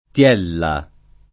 [ t L$ lla ]